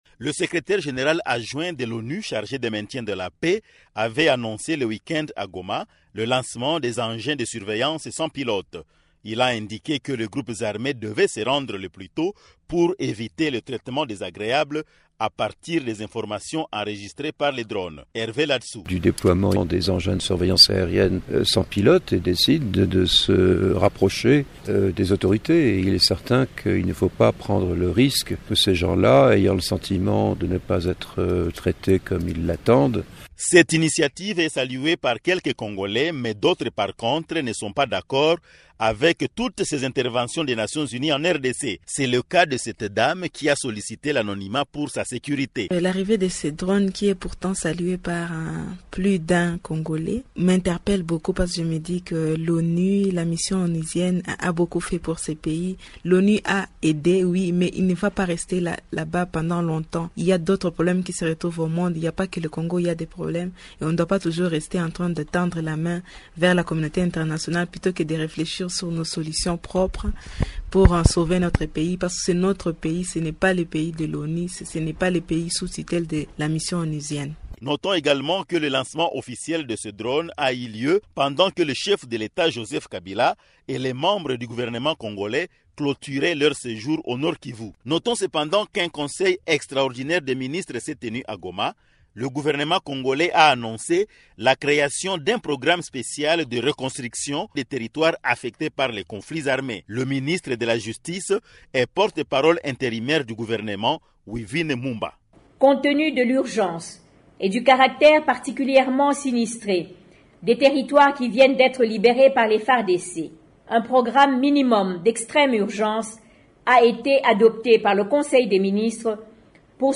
Un reportage